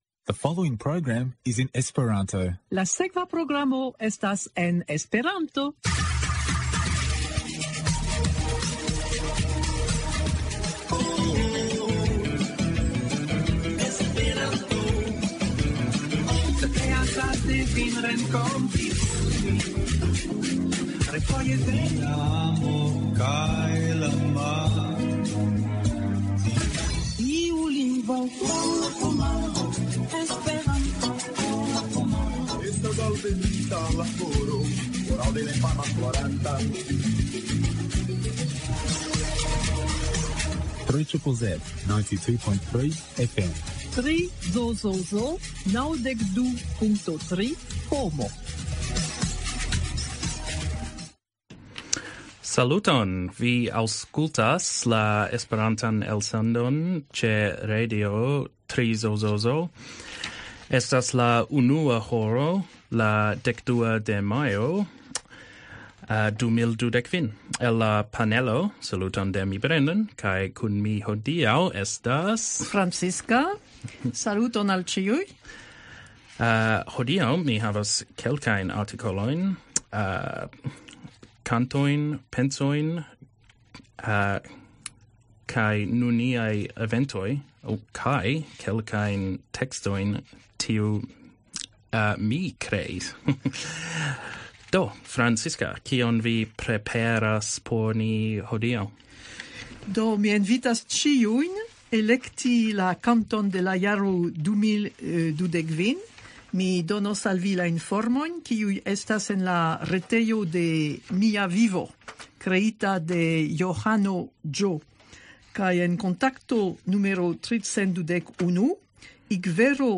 En la Melburna Esperanto-komunumo estas teamo, kiu regule elsendas programon en Esperanto kadre de la komunuma radio 3ZZZ.
The Melbourne Esperanto community has a dedicated and enthusiastic group of people who produce an hourly Esperanto radio program each week.